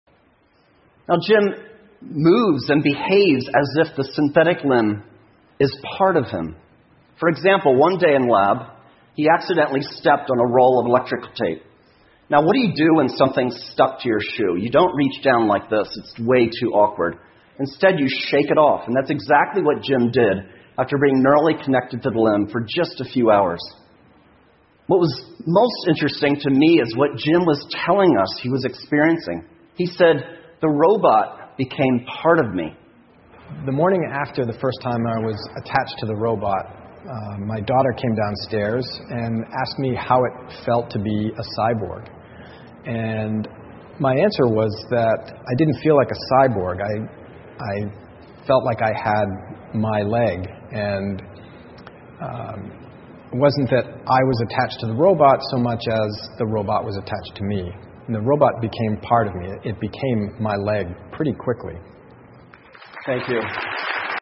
TED演讲:如何成为生化人并扩展人类潜能() 听力文件下载—在线英语听力室